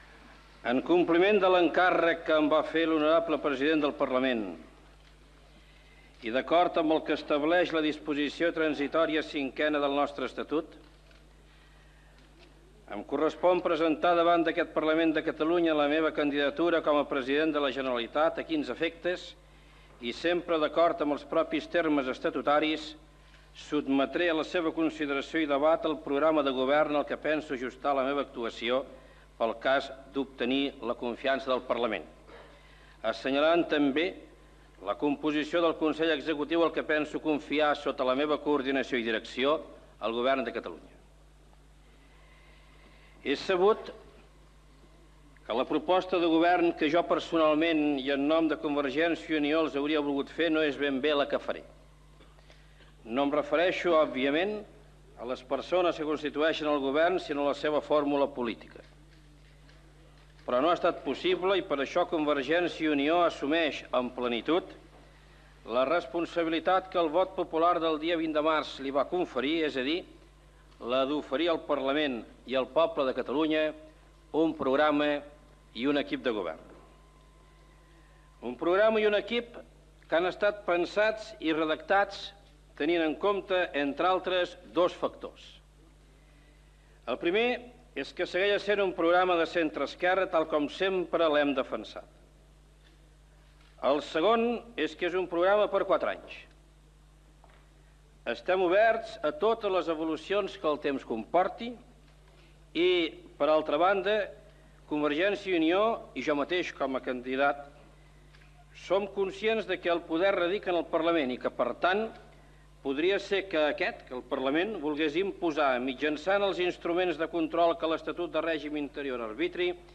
b469f5ff5b1aba05edd8647ecde8fae744d69283.mp3 Títol Ràdio Barcelona Emissora Ràdio Barcelona Cadena SER Titularitat Privada estatal Descripció Inici del discurs d'investidura com a president de la Generalitat de Jordi Pujol al Parlament de Catalunya. Gènere radiofònic Informatiu